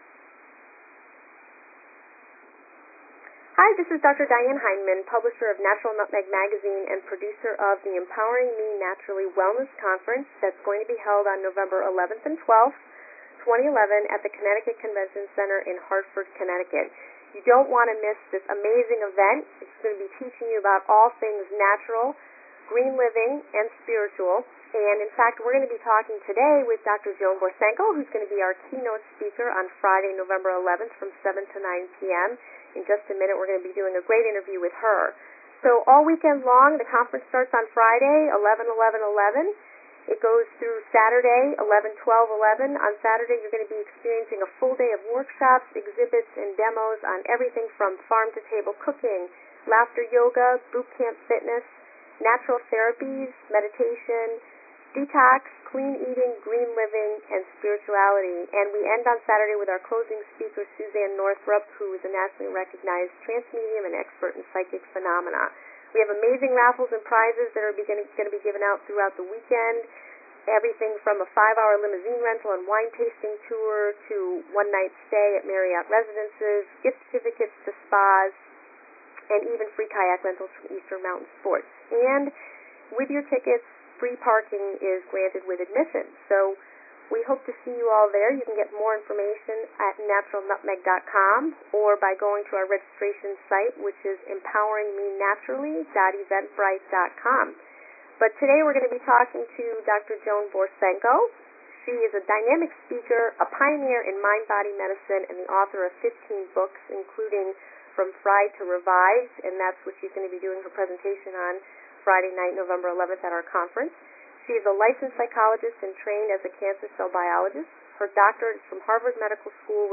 Joan Borysenko interview
Joan-Borysenko-interview.mp3